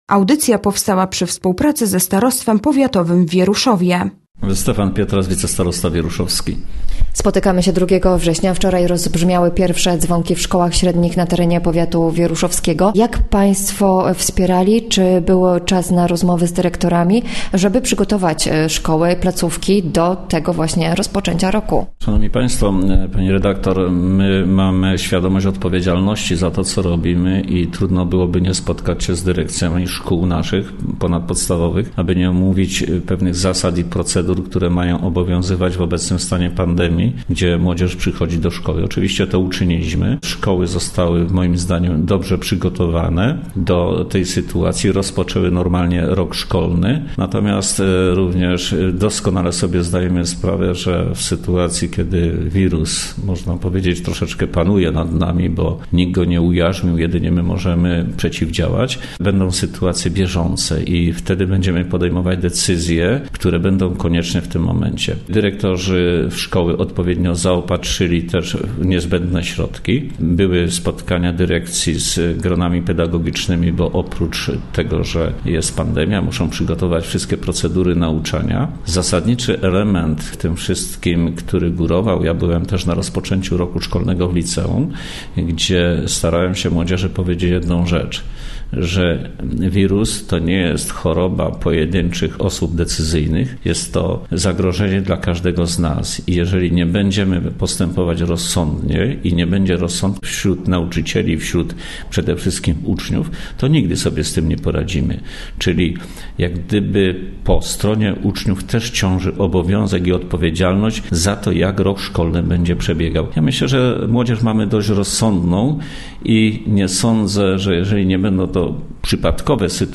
Gościem Radia ZW był wicestarosta powiatu wieruszowskiego, Stefan Pietras